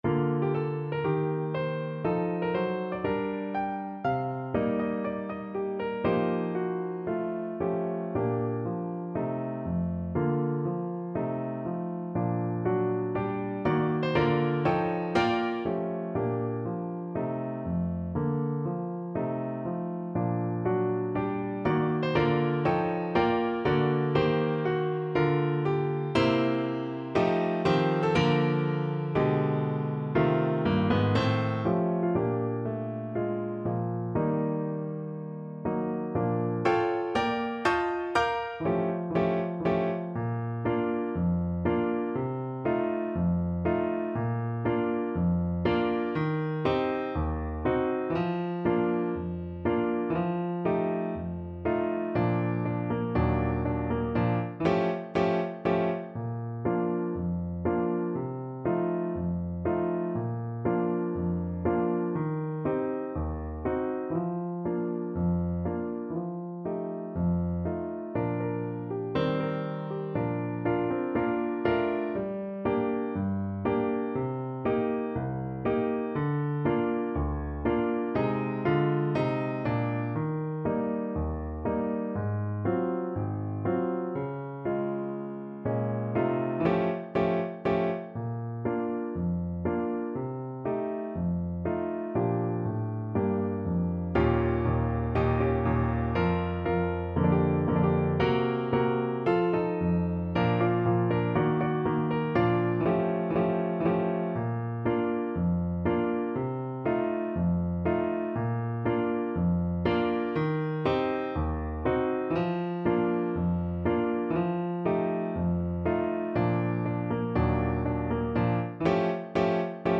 ~ = 120 Moderato